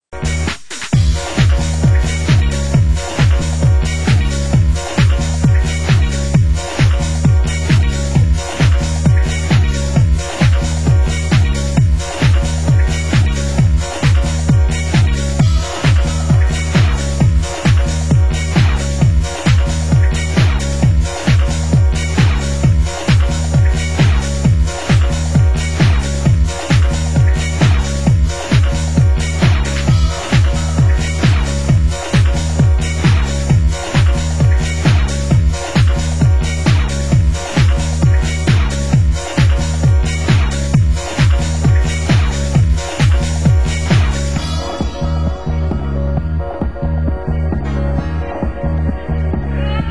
Genre Tech House